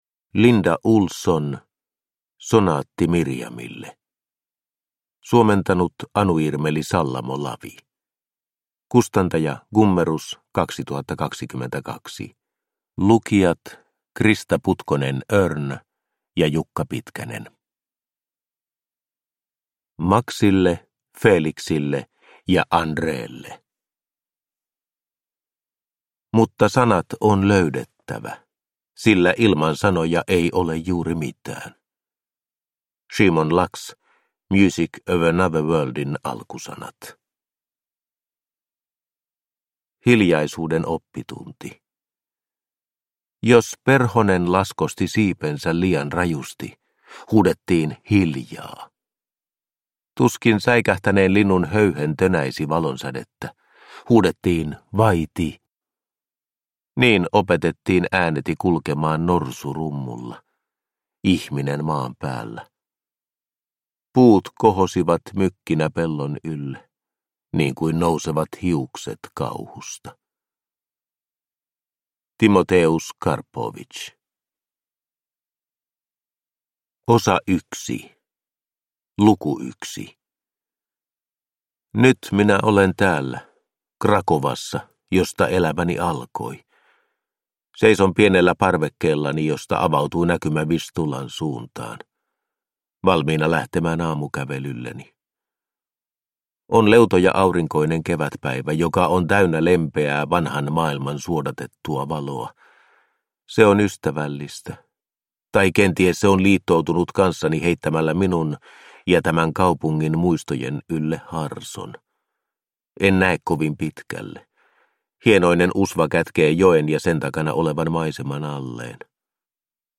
Sonaatti Miriamille – Ljudbok – Laddas ner